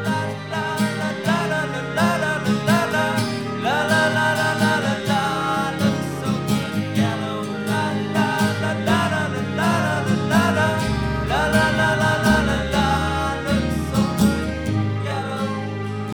Panning (2854.0K) – This has music going from the left to the right speaker and then right to left.